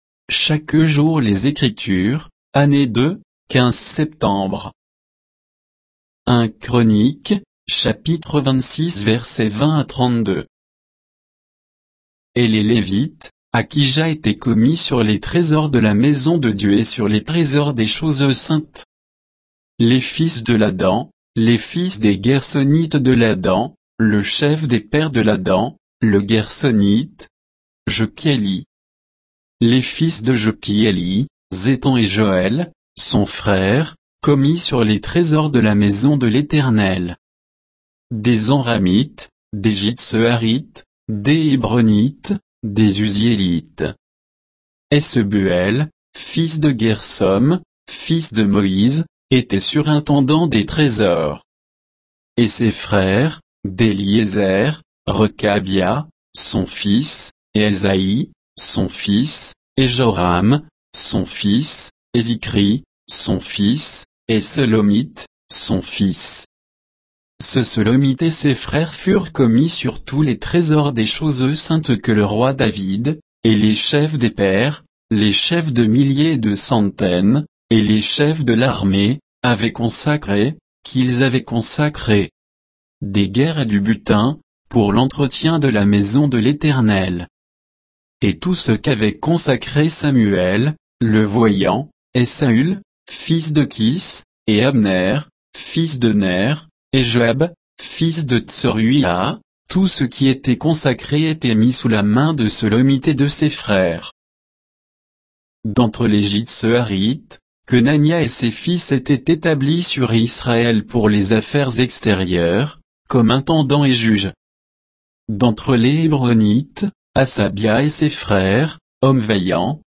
Méditation quoditienne de Chaque jour les Écritures sur 1 Chroniques 26, 20 à 32